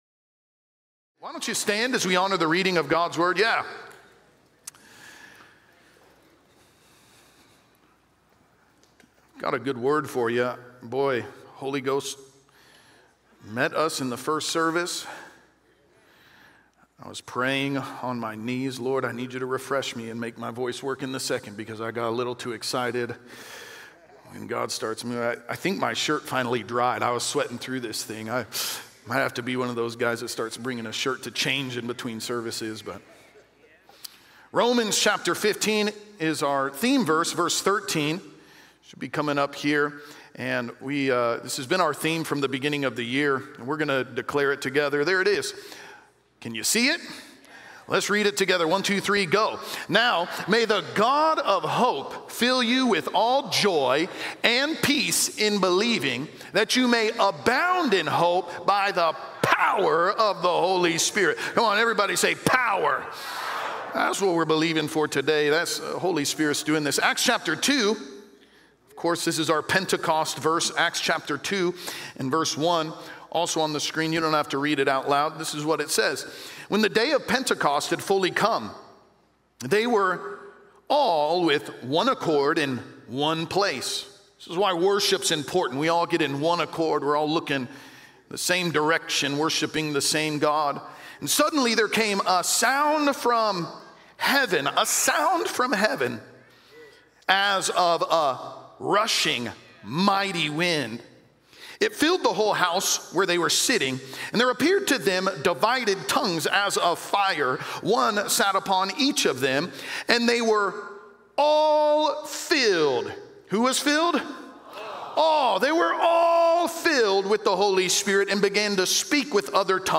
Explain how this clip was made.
Pentecost | Acts 2:1-4 | Trinity Church Cedar Hill